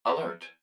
042_Alert.wav